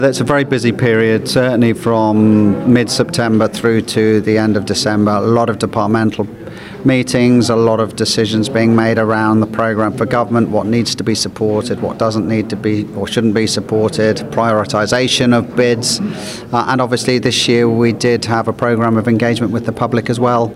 Alfred Cannan says the past few months have been busy with preparation: